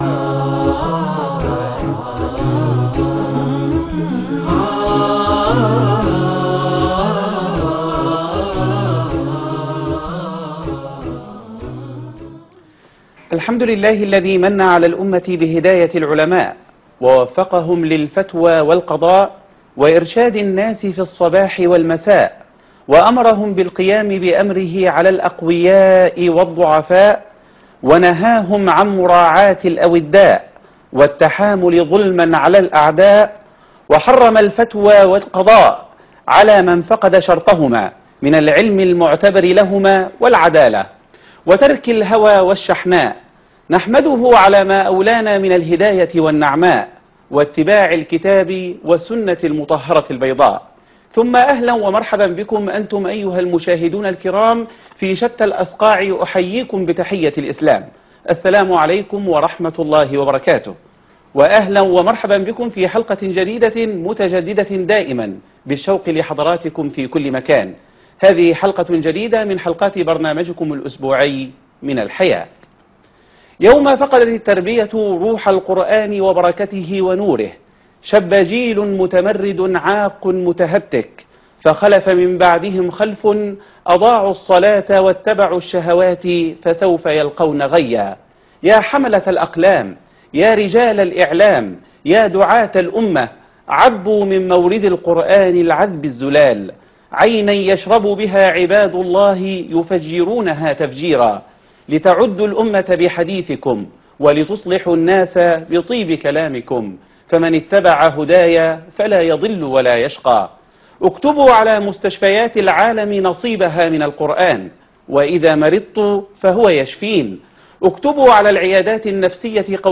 تربية الابناء بين الواجبات و التحديات ( 2/11/2025 ) من الحياة لقاء خاص مع الشيخ محمد حسين يعقوب - فضيلة الشيخ محمد حسين يعقوب